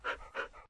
mob / wolf / panting / shake.ogg
shake.ogg